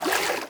swim4.wav